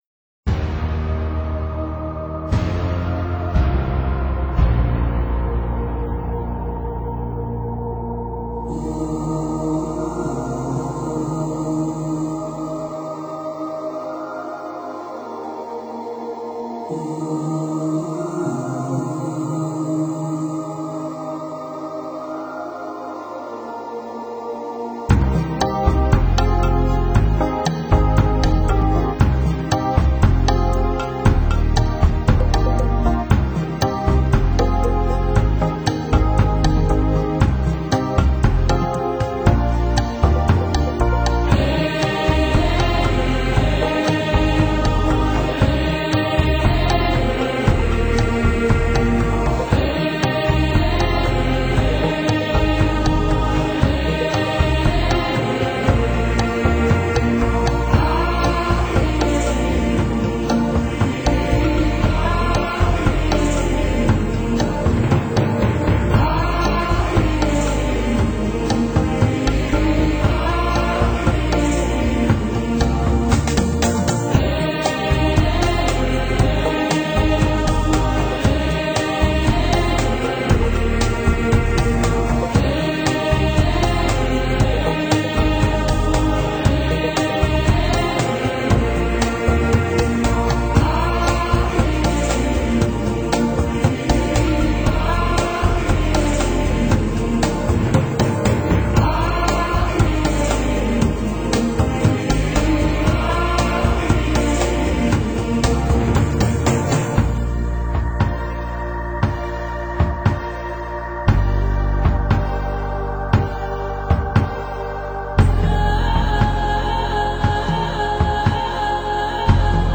乐曲风格变化多姿，融合了西方、印度和日本的音乐元素，洋溢着欢快自由的生命气息。